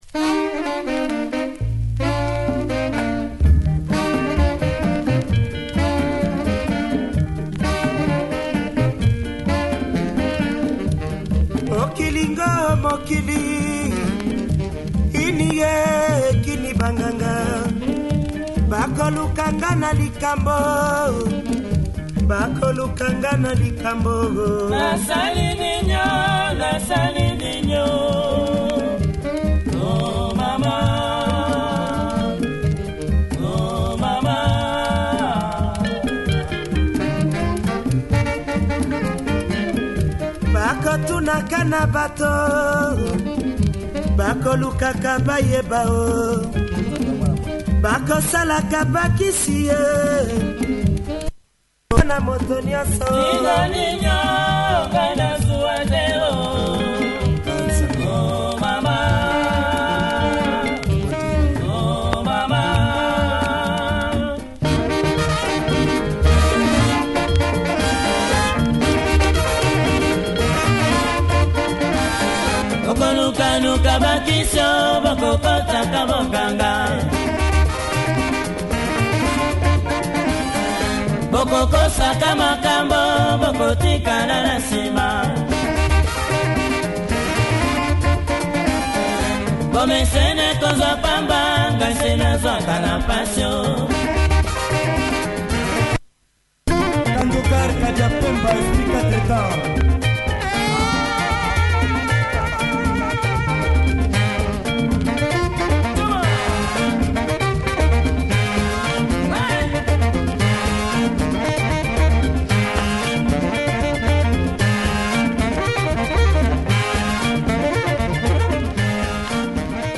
Great groover
starts slow but fireworks skyrockets mid-way!